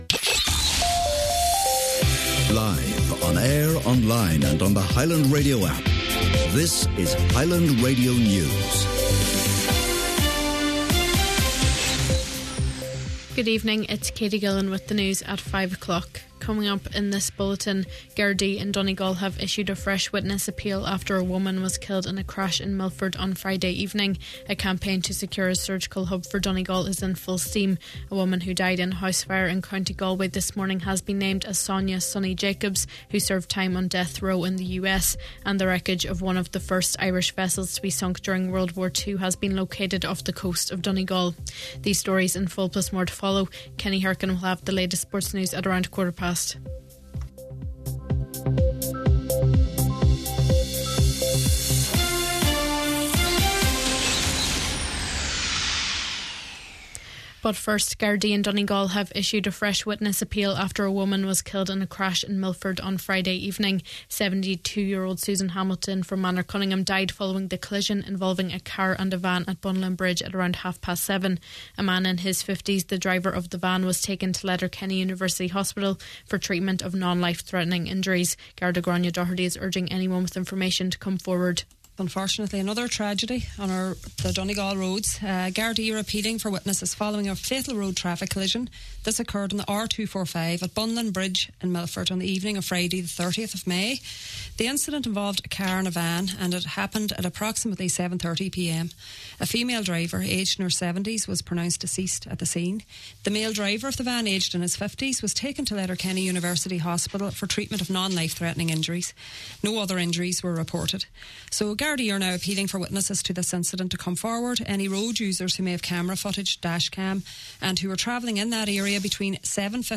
Main Evening News, Sport and Obituaries – Tuesday June 3rd